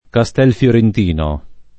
kaStHlfLorent&no] top. (Tosc.)